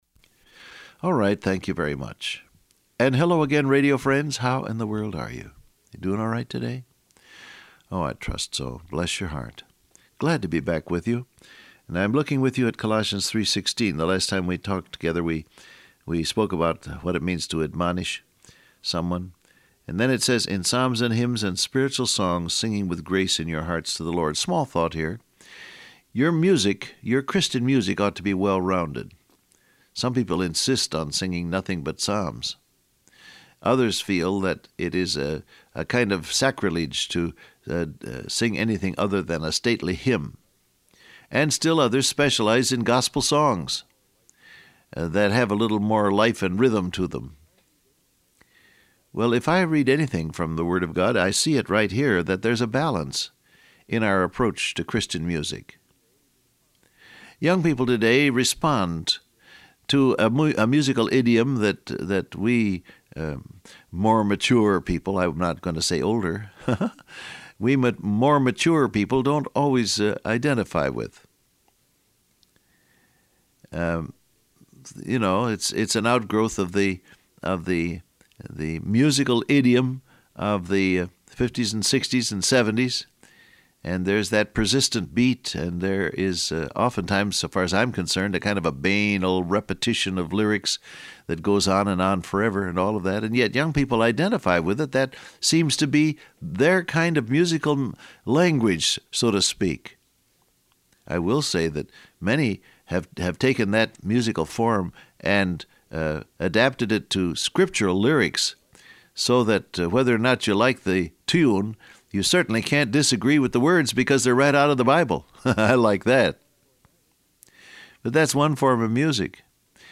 Download Audio Print Broadcast #1917 Scripture: Colossians 3:16-17 , John 14 Transcript Facebook Twitter WhatsApp Alright, thank you very much.